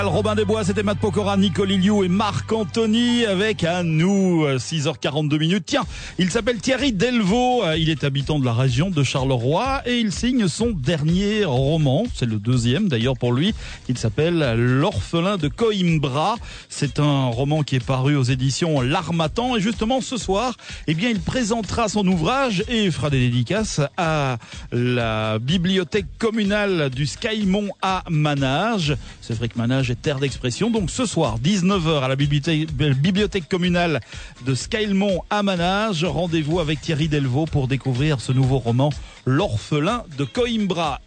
Émission Radio
Annonce radio (Vivacité)